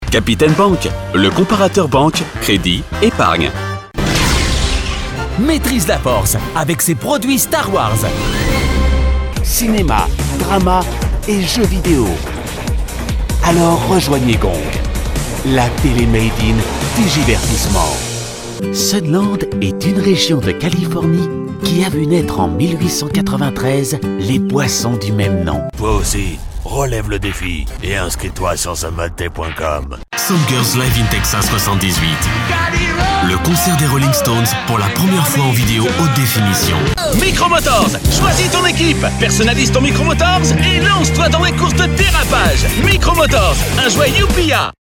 Conversational Corporate Neutral